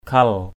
/kʱʌl/ 1.